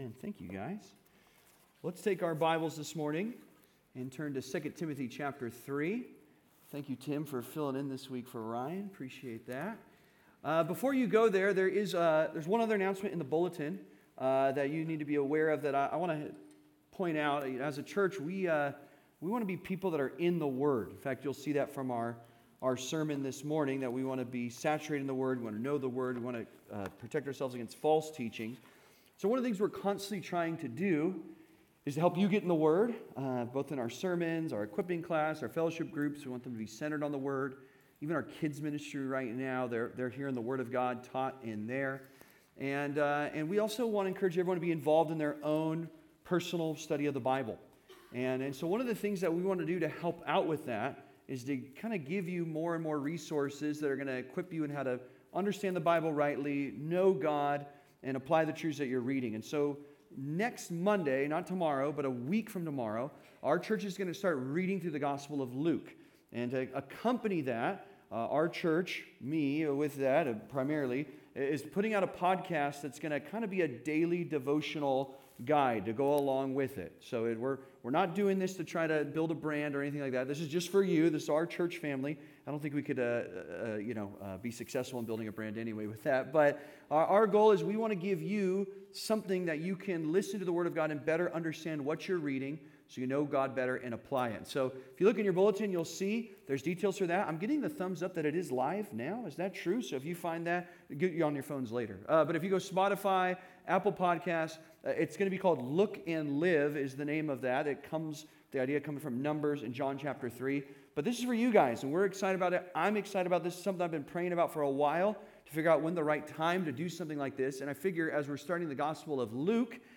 Putting False Teachers on Silent (Sermon) - Compass Bible Church Long Beach